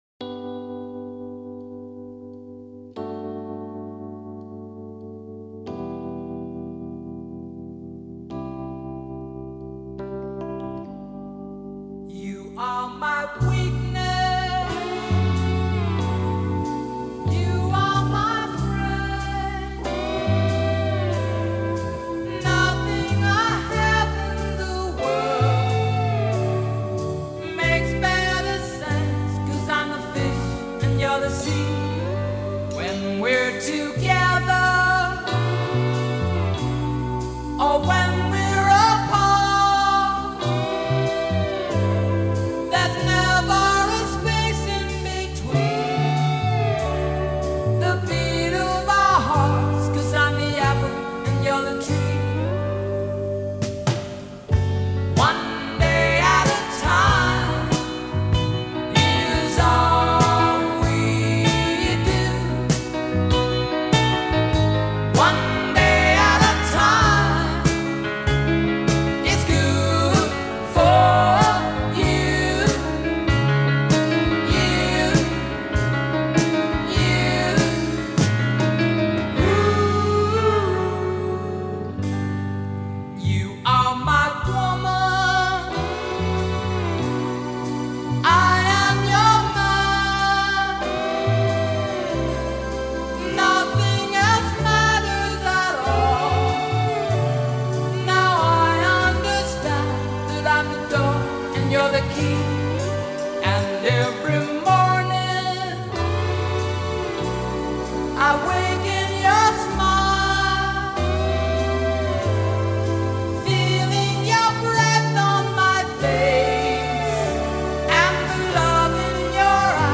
guitar
B-side of the 7″ single